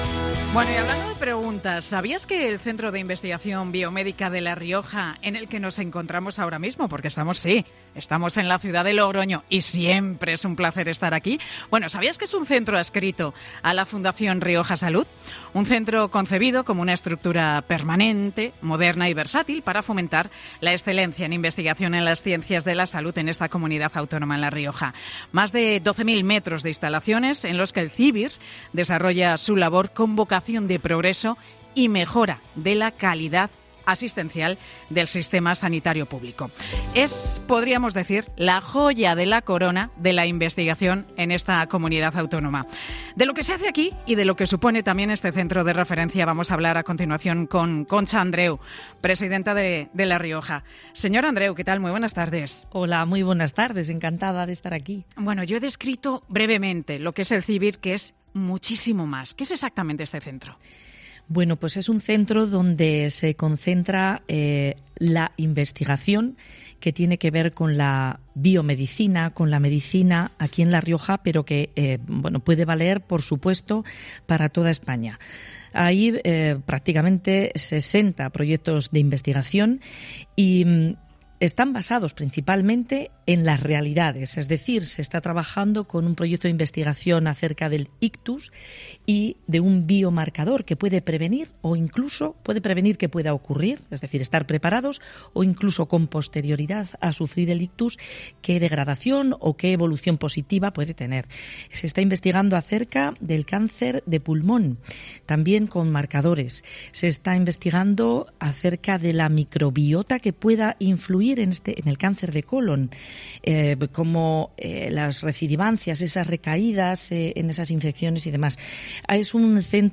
Pilar García Muñiz y el equipo de Mediodía COPE se han desplazado a Logroño para comprobar los retos que se están consiguiendo en salud en el CIBIR, el Centro de Investigación Biomédica de La Rioja, que es la joya de la corona de la investigación en esta comunidad autónoma.